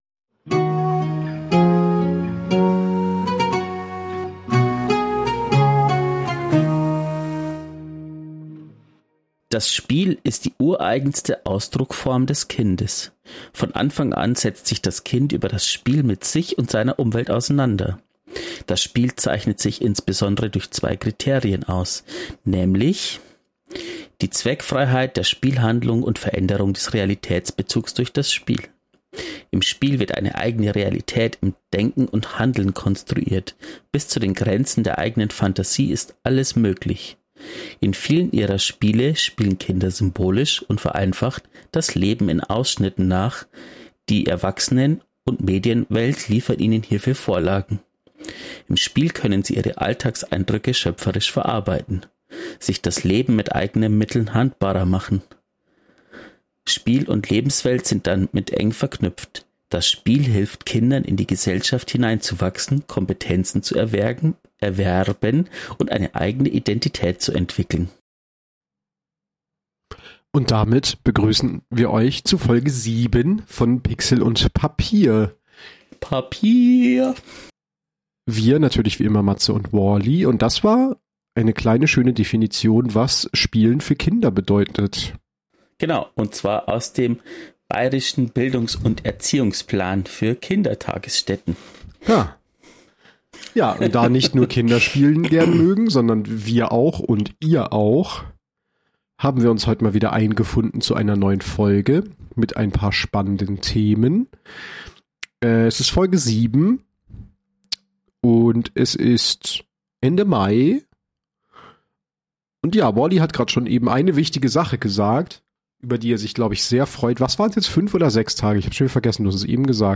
Deshalb haben wir beschlossen, auch darüber zu quatschen.